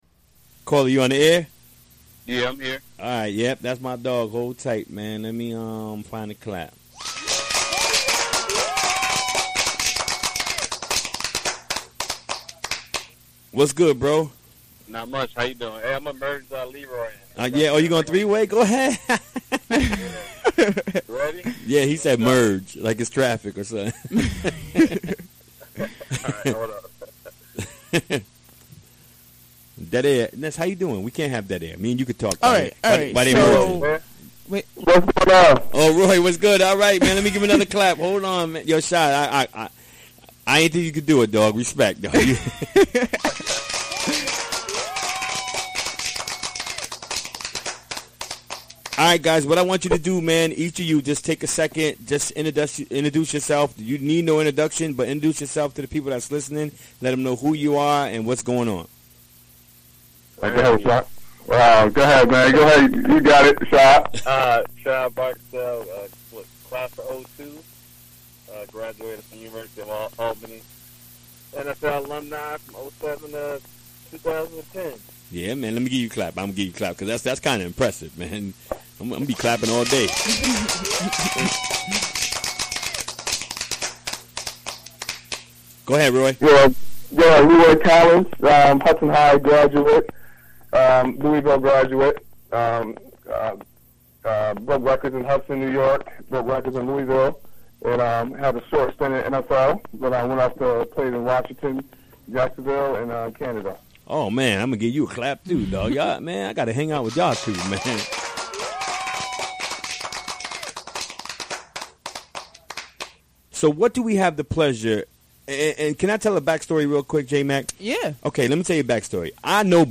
Recorded during the WGXC Afternoon Show Wednesday, July 19, 2017.